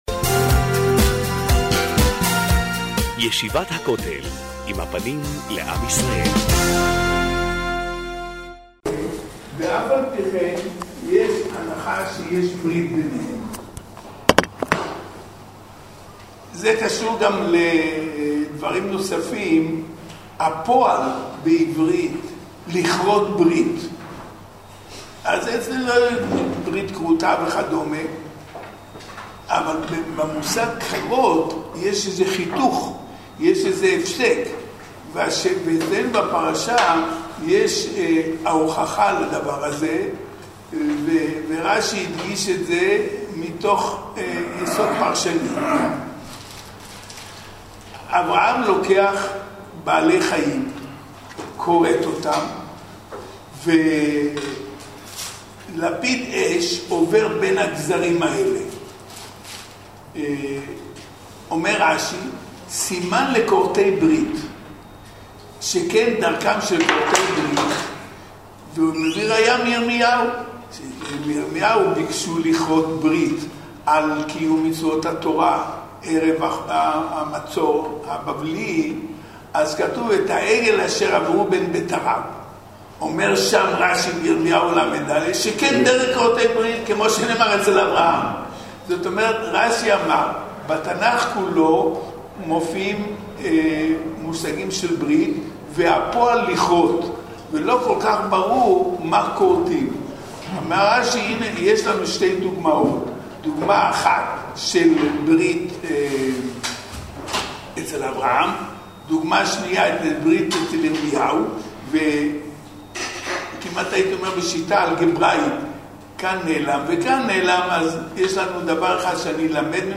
חשוון תשע"ז להאזנה לשיעור: https